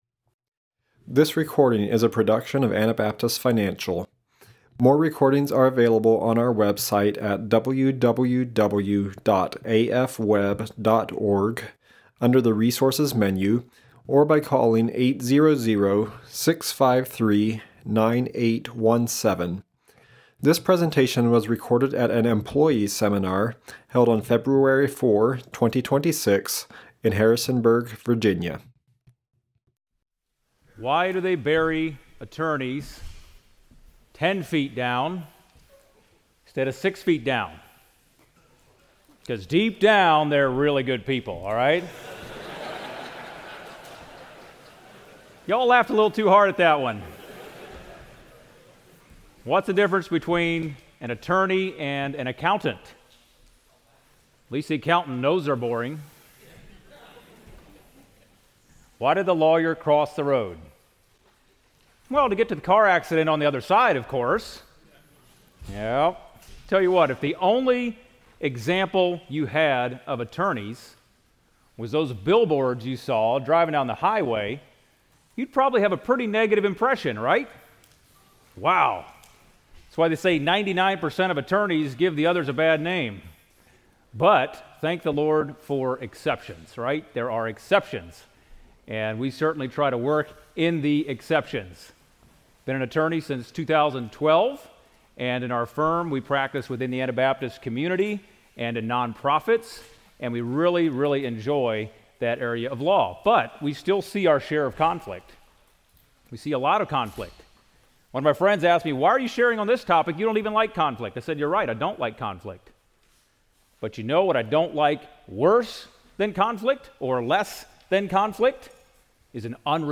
Virginia Employee Seminar 2026